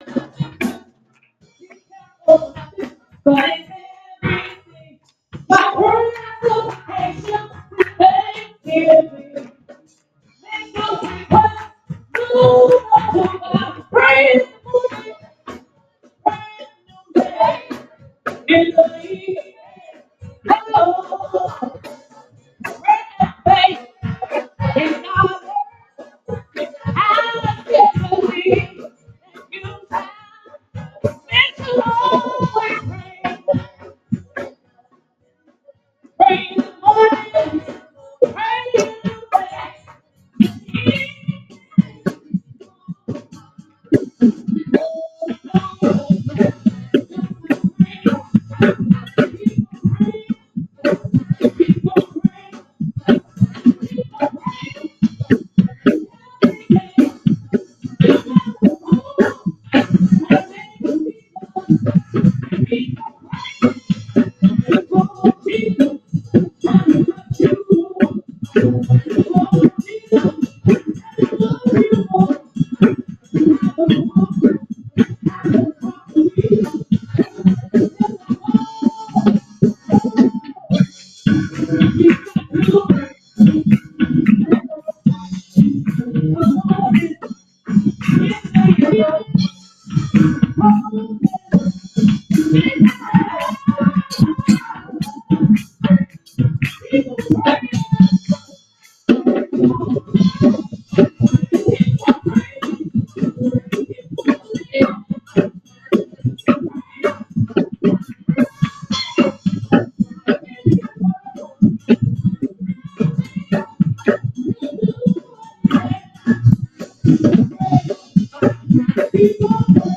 Bb F# 4/4 100